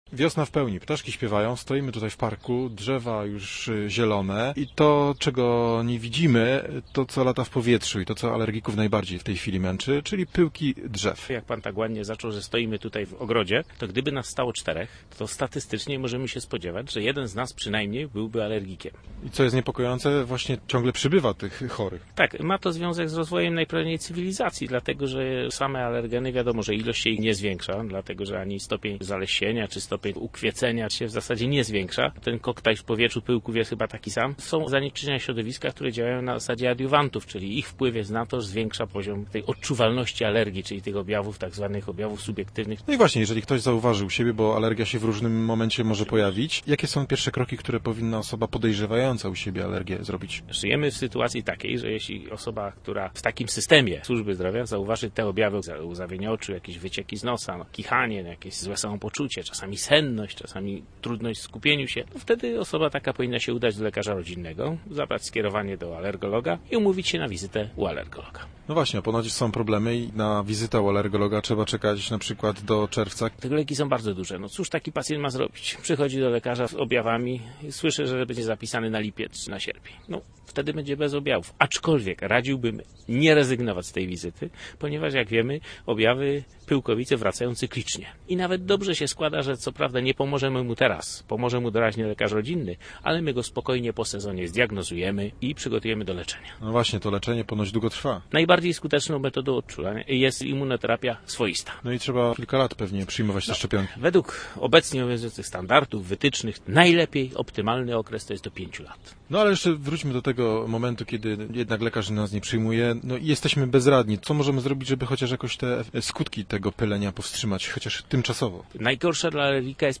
Czy na pyłki jest sposób i jak sobie ułatwić życie wiosną? Nasz reporter rozmawiał o tym z alergologiem.